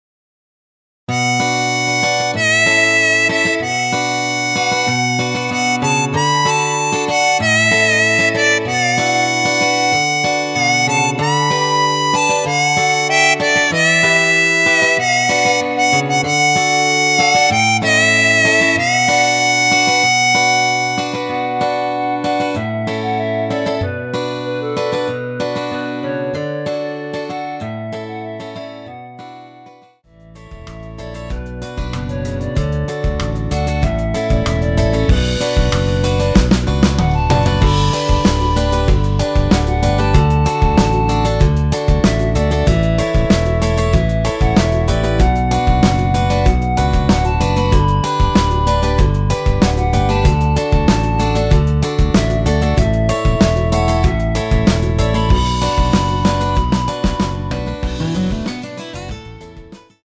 원키에서(+7)올린 멜로디 포함된 MR입니다.
Bm
멜로디 MR이라고 합니다.
앞부분30초, 뒷부분30초씩 편집해서 올려 드리고 있습니다.